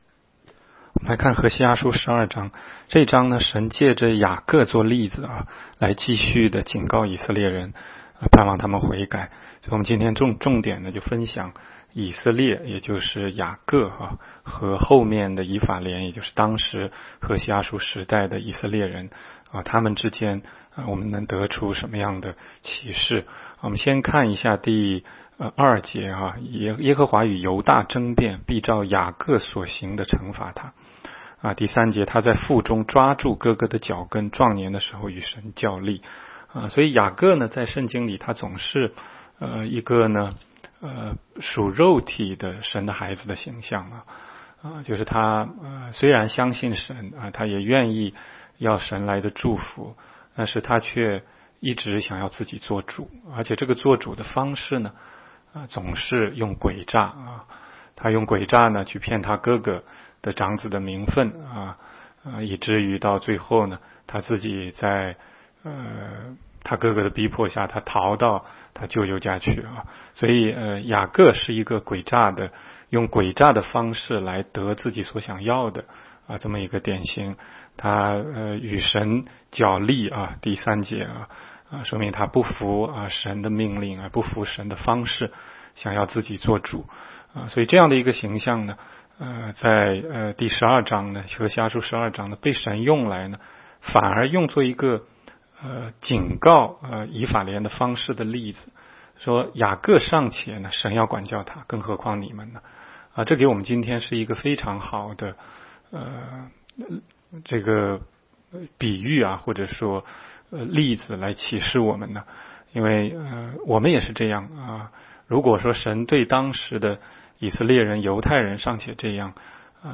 16街讲道录音 - 每日读经 -《何西阿书》12章